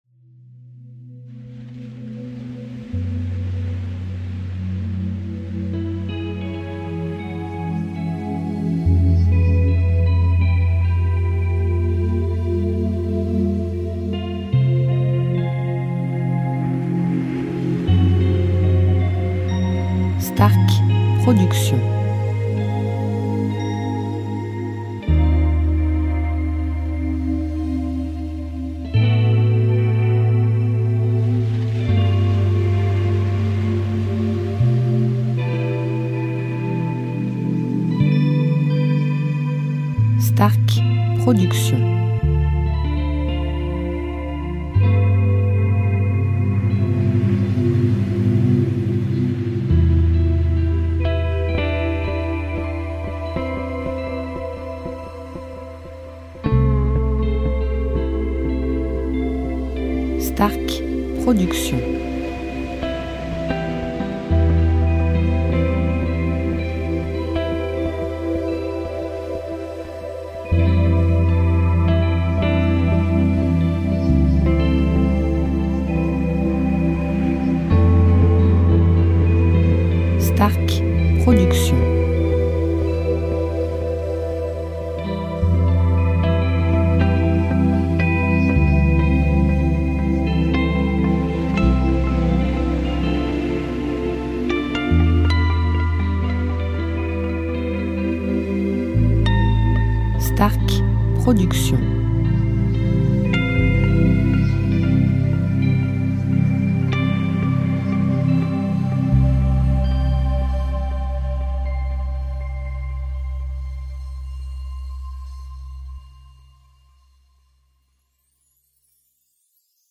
style Californien